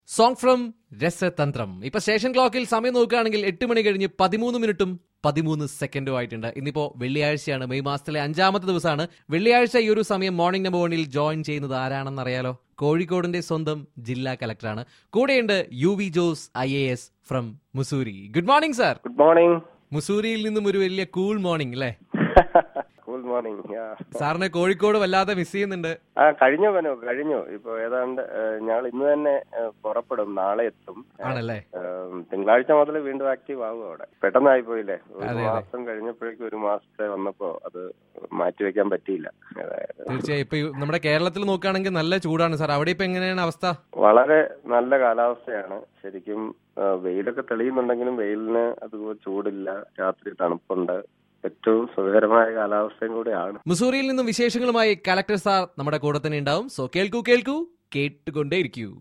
COLLECTOR LIVE FROM MUSSURIE